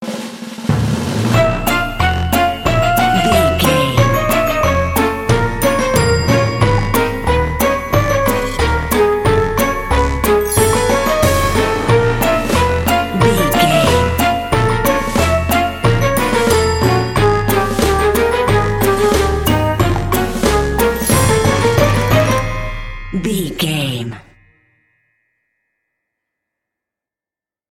Aeolian/Minor
B♭
percussion
synthesiser
horns
strings
circus
goofy
comical
cheerful
perky
Light hearted
quirky